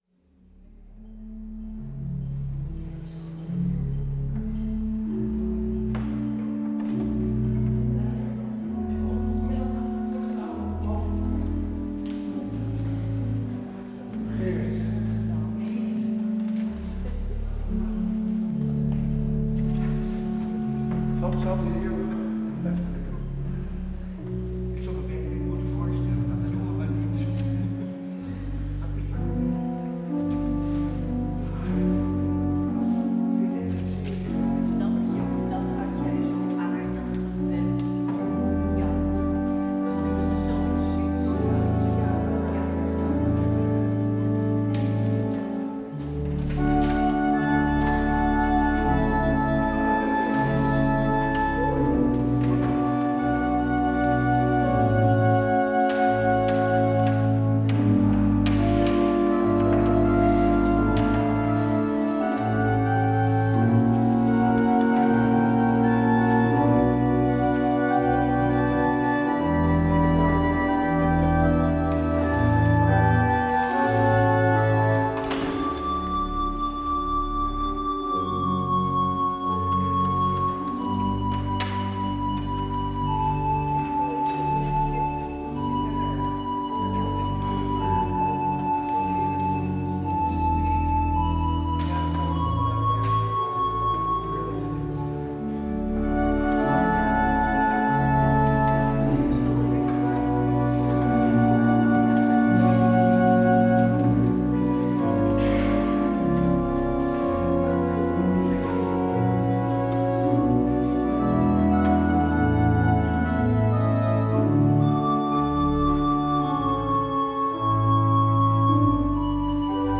 Beluister deze kerkdienst hier: Alle-Dag-Kerk 28 januari 2026 Alle-Dag-Kerk https
Het slotlied is: Gezang 465: 1, 2 en 5.